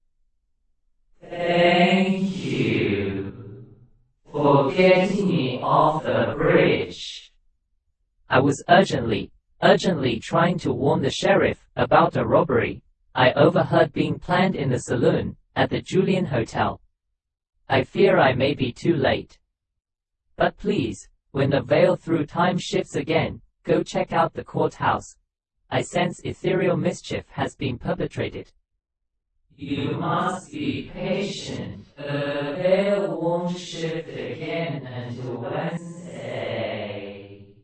George-Way-Mandarin-Pulcherrima-paulstretched-and-ghosted.mp3